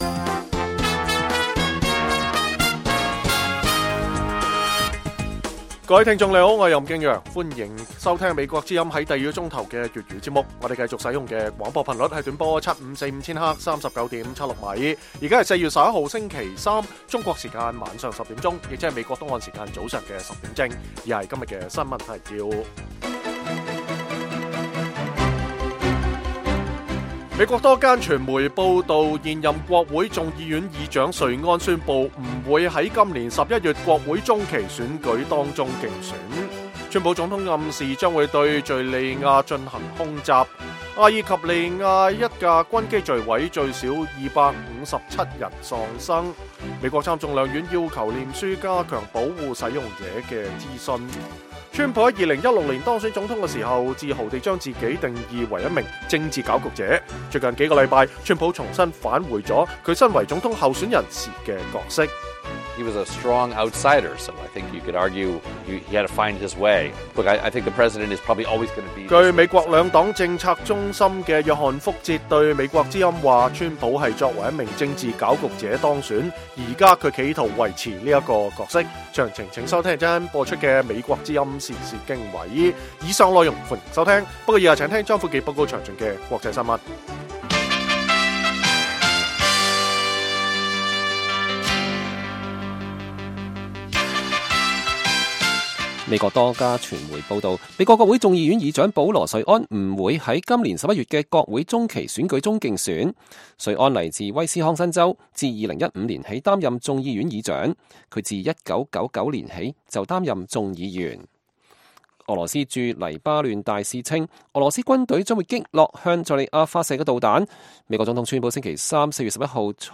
北京時間每晚10－11點 (1400-1500 UTC)粵語廣播節目。內容包括國際新聞、時事經緯和社論。